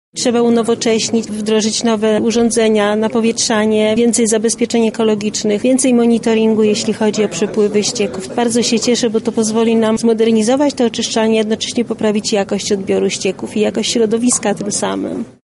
Na jakie działania zostaną środki przyznane oczyszczalni ścieków w Snopkowie mówi Wójt Gminy Jastków Teresa Kot: